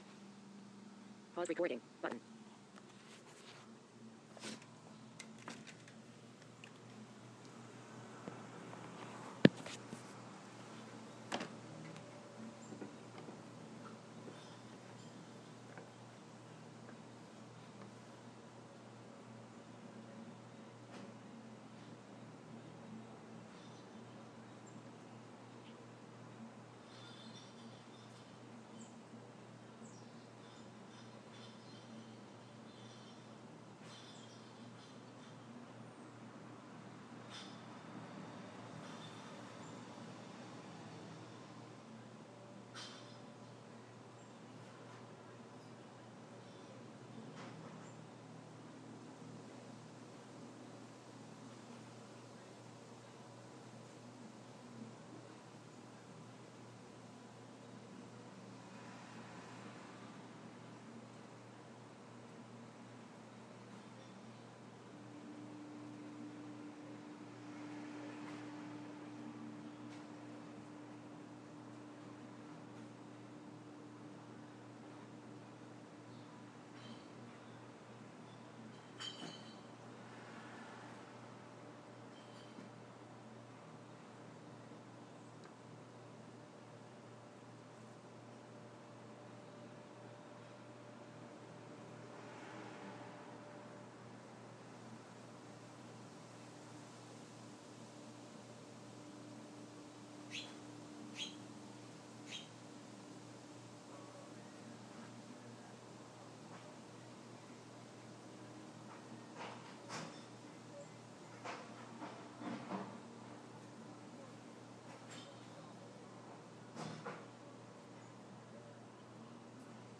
Finches at my feeder